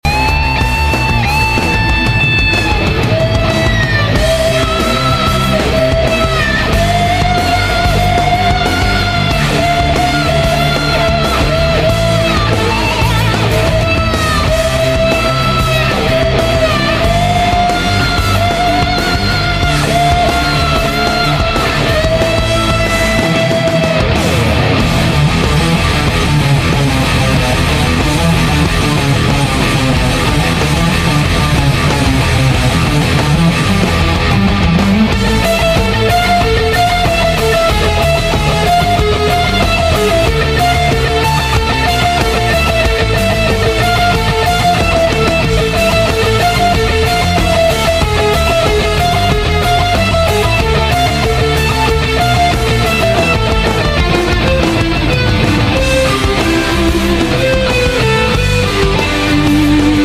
Solo guitar cover